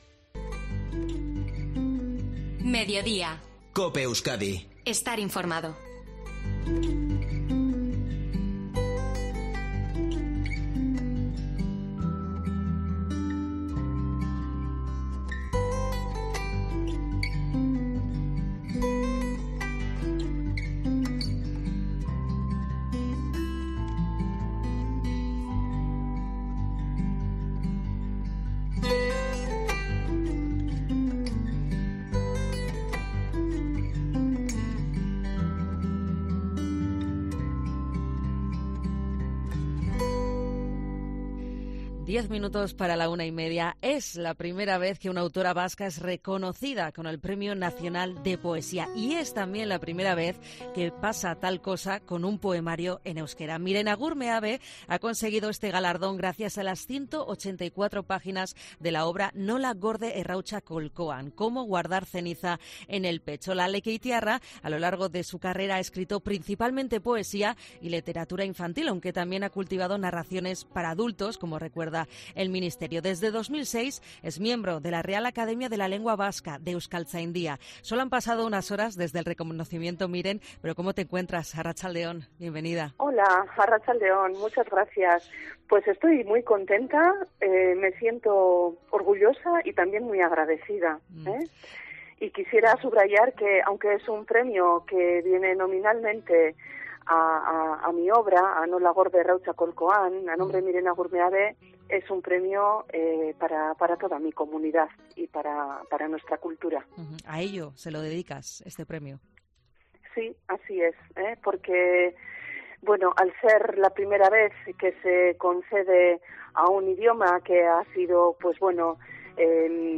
Miren Agur Meabe, en COPE Euskadi